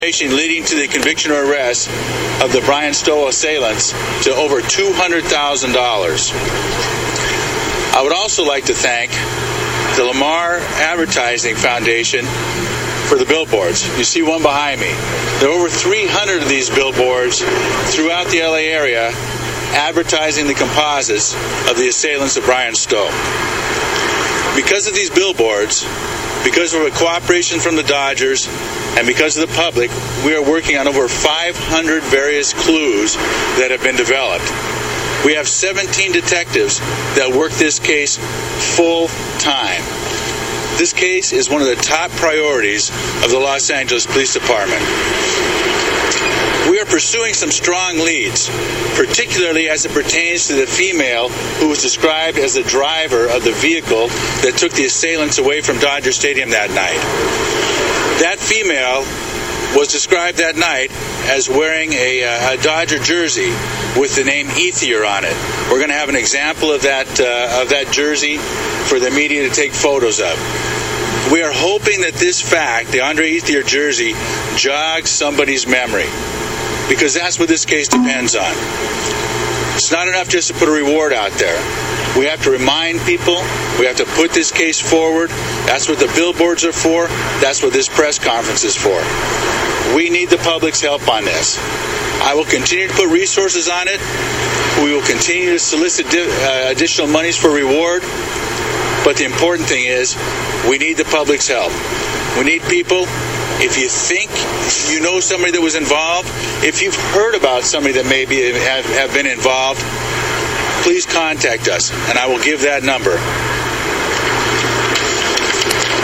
Dodger stadium billboards podcast